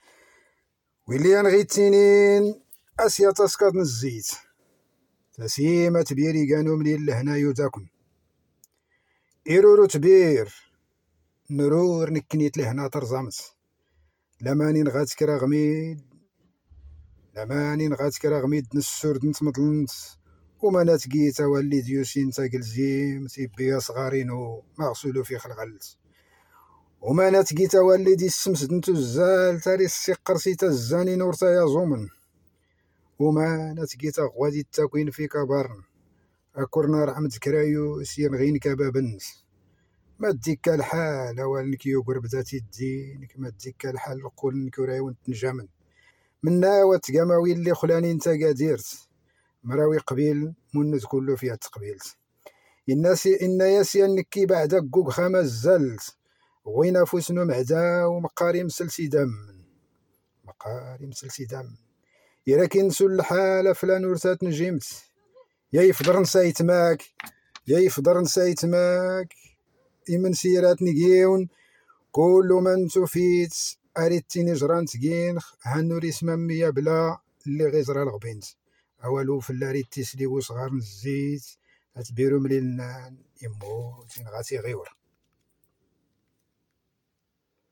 تامديازت/شعر